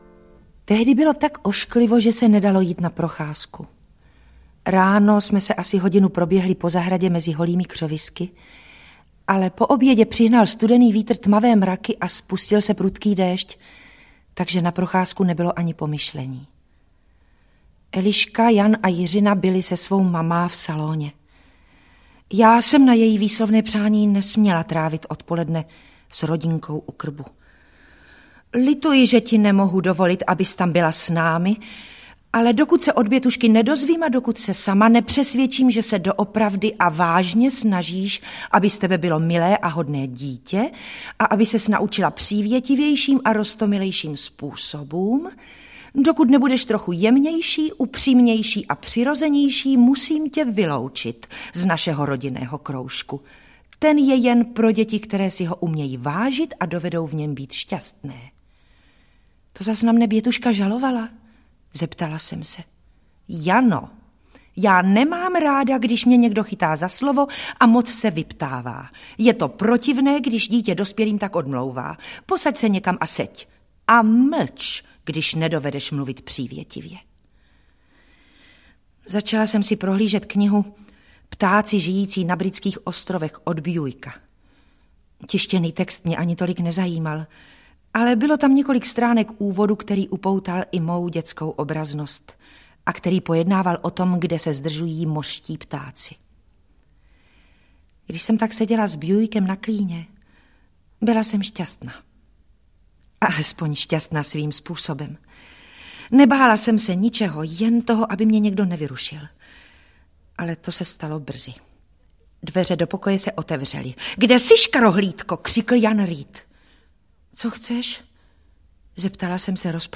Jana Eyrová audiokniha